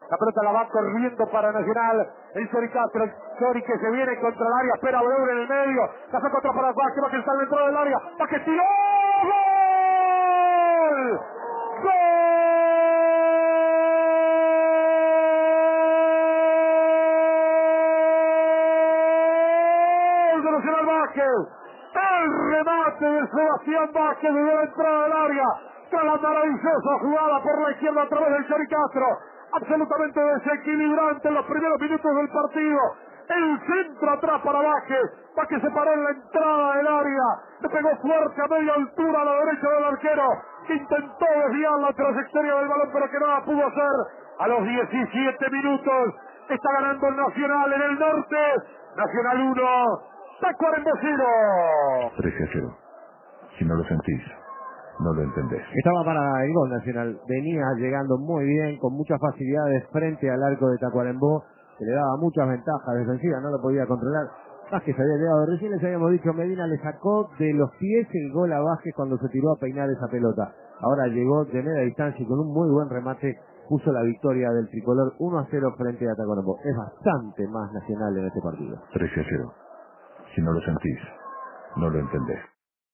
Radiodeportivo Escuche el gol de Nacional frente a Tacuarembó, en el relato